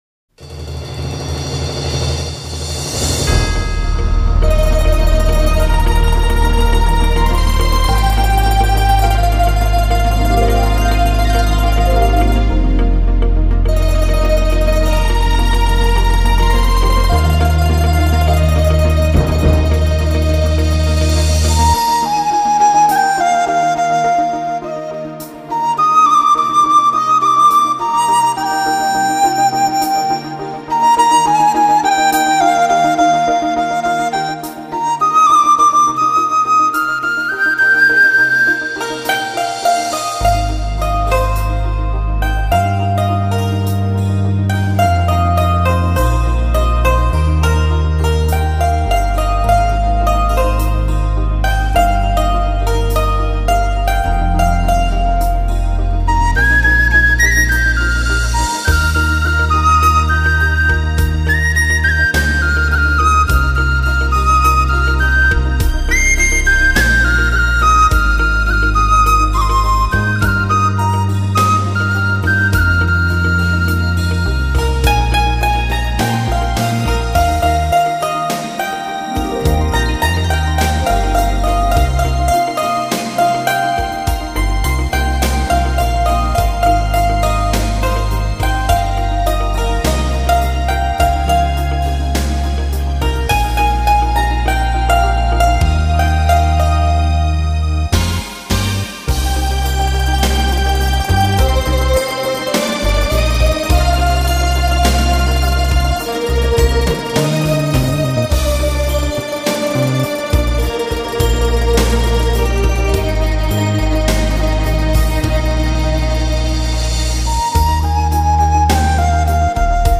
此系列编曲方面比较柔和一些，适合闲情时候欣赏的民乐器轻音乐。